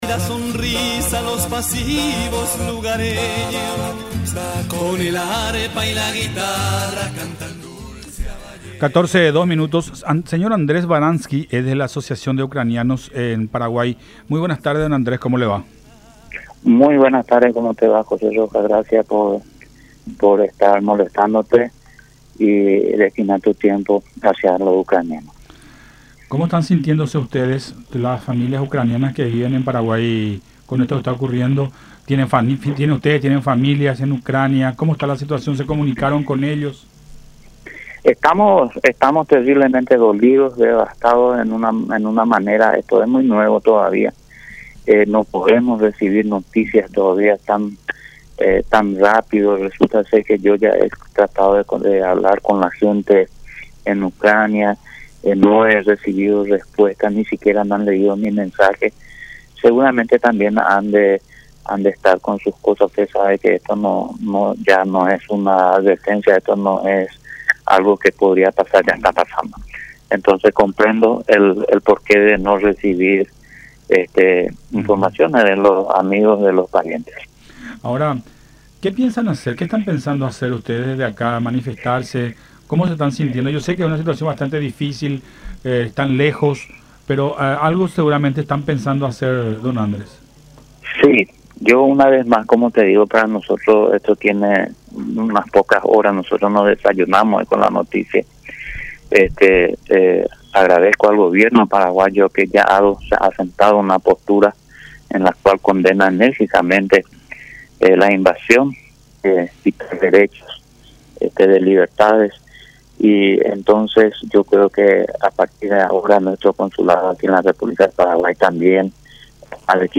en diálogo con Buenas Tardes La Unión.